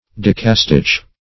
Search Result for " decastich" : The Collaborative International Dictionary of English v.0.48: Decastich \Dec"a*stich\, n. [Pref. deca- + Gr. sti`chos a row, a line of writing, a verse.] A poem consisting of ten lines.